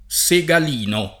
il Segal&n’n’o e ffreddol1So r$di] (Redi) — anche segalino [
Segal&no], meno com. nel sign. fig., meno raro nel proprio — sim. i cogn. Segalin [Segal&n], Segalina, Segalini